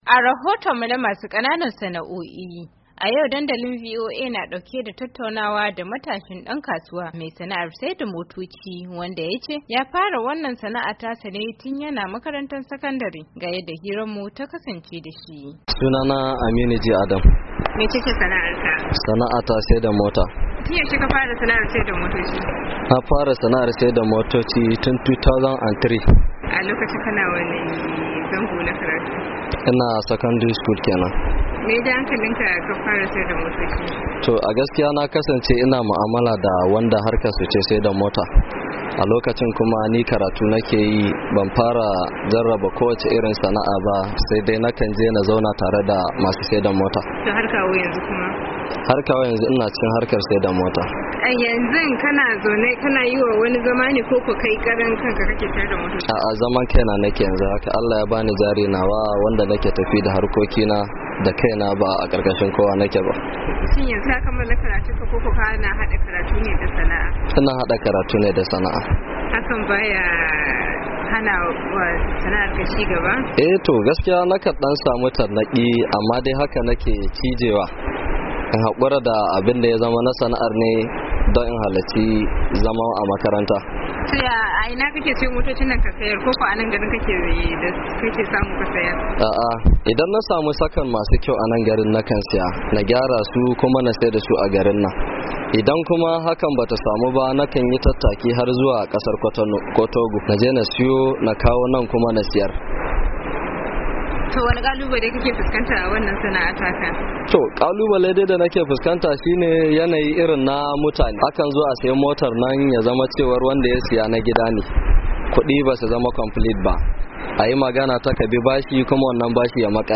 Matasa kuka ma sana’a kowace iri kuma kada ku renan sana’a. Wani matashi me sana’ar saida motoci kenan yayi wannan kiran a wani zantawa da mukayi da shi.